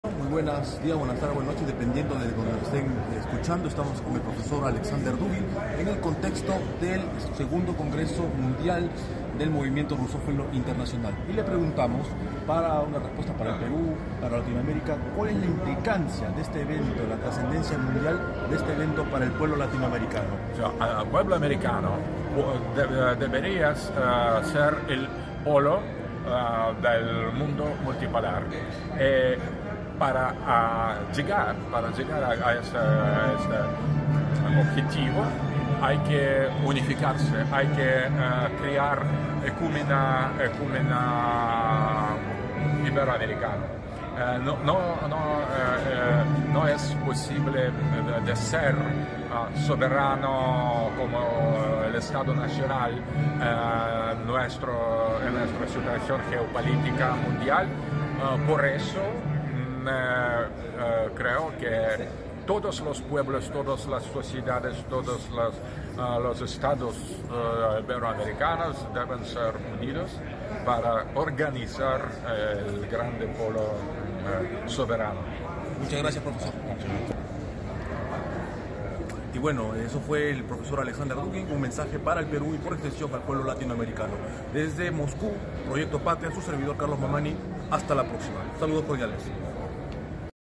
Breve abordaje al Prof.  Aleksandr Duguin en el histórico "Foro Multipolar", bajo contexto del 2do Encuentro Mundial del "Movimiento Rusófilo Internacional" (MIR), celebrado los días 25, 26 y 27 de febrero en Moscú, en inmediaciones del "Clúster Lomonosov del Centro de Innovación Científica y Tecnológica Vorobyovy Gory" de la Universidad Estatal de Moscú (МГУ). En esta oportunidad el Prof.  Aleksandr Duguin, sociólogo, filósofo y geopolítico, considerado como referente mundial de la Teoría del Mundo Multipolar y principal ideólogo de la Rusia contemporanea, brindó un mensaje en clave continental a Nuestra América: